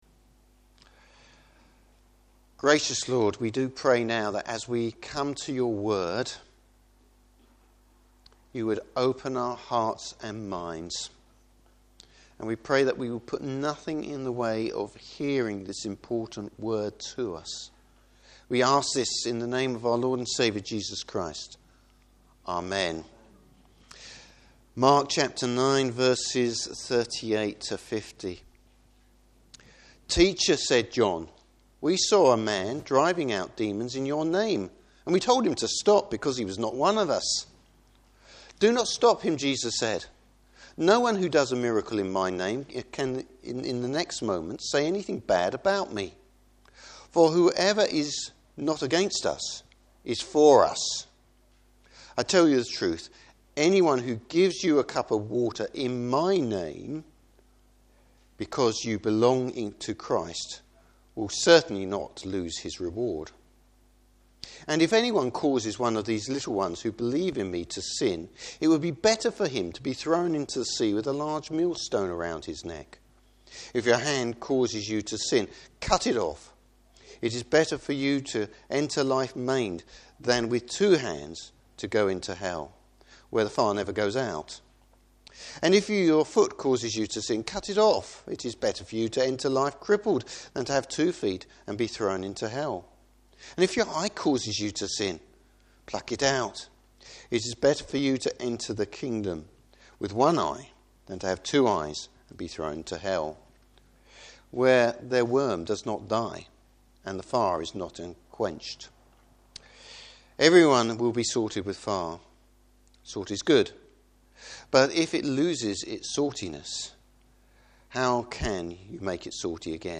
Service Type: Morning Service How is being a Christian reflected in the way we live?